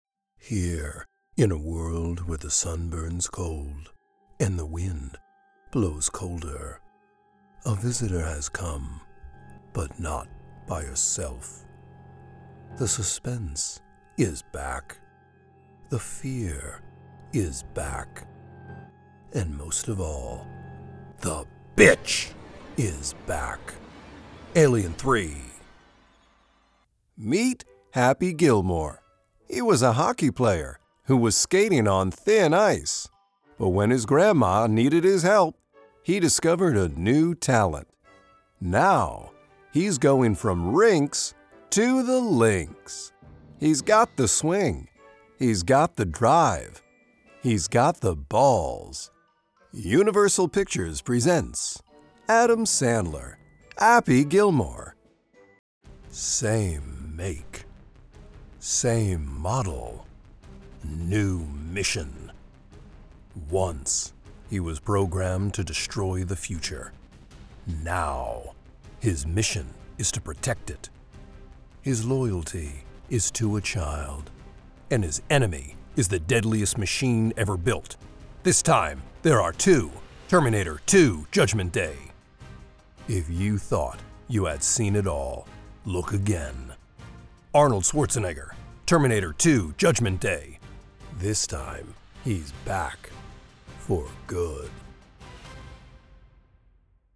Male
Within the bass and baritone range, my voice can exhibit a casual guy-next-store vibe to authoritative Voice-of-God style to upbeat and energized.
Movie Trailers
Vog Trailers: Horr, Com, Act
07173TRAILER_DEMO_mixdown.mp3